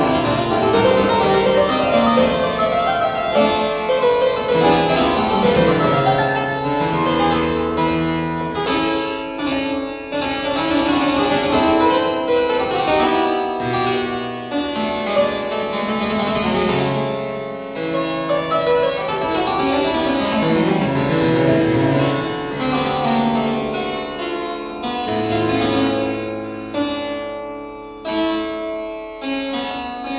la mineur
0 => "Musique classique"